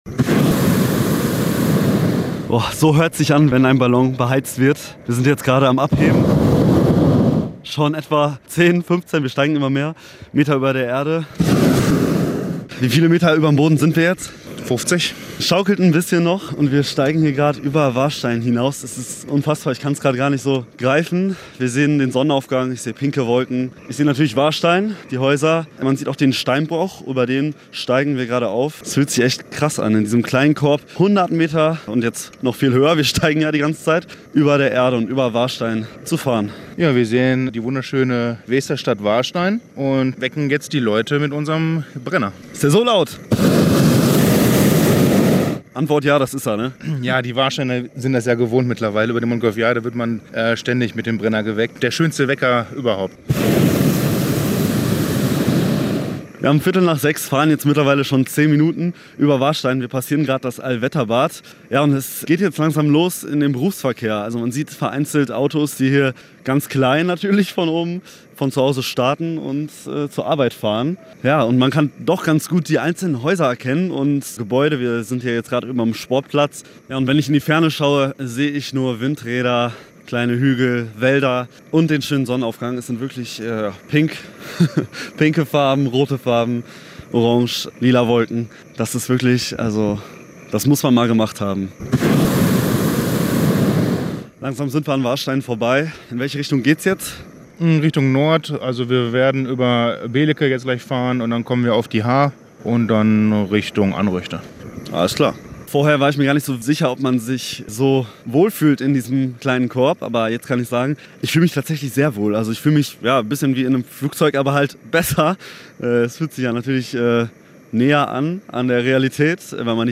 Eine Fahrt mit dem Heißluftballon über den Kreis Soest - Hellweg Radio
repo-lang_bei_einer_heissluftballonfahrt_dabei_-_-v1.mp3